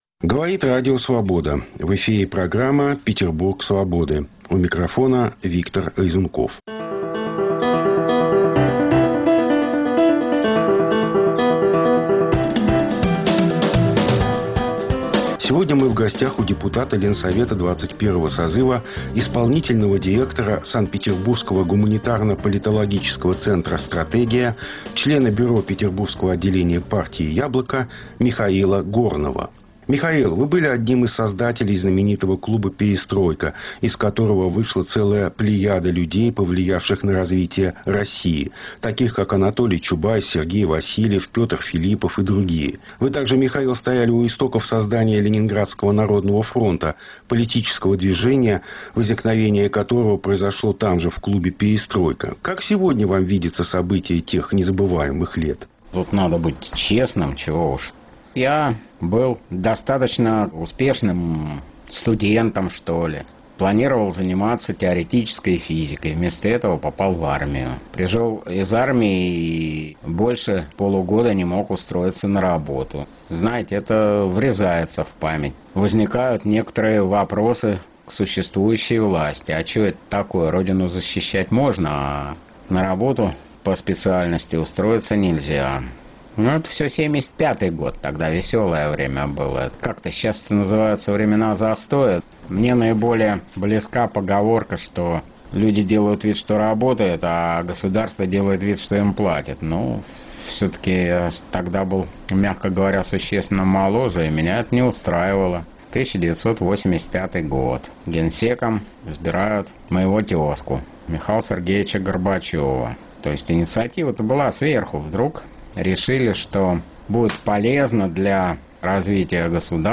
Обсуждаем с депутатом Ленсовета 21-го созыва, членом бюро петербургского отделения партии "Яблоко" Михаилом Горным